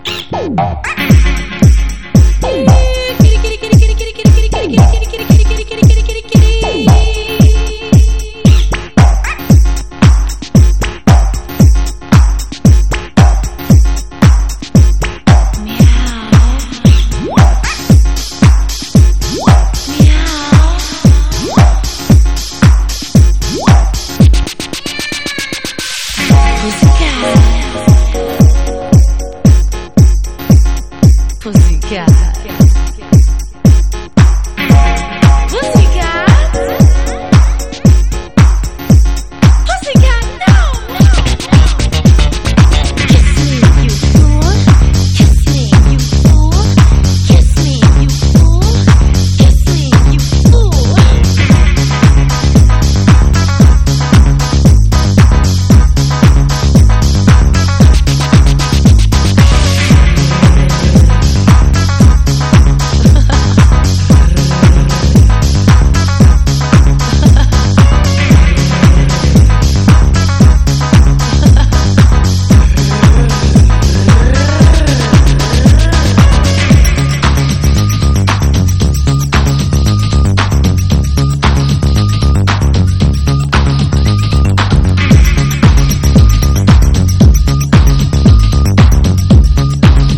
JAZZ / JAZZ VOCAL / JAPANESE / 50'S / VOCAL / EXOTICA
オリエンタルなアレンジが秀逸な
ドリーミィなアレンジにまどろんで下さい！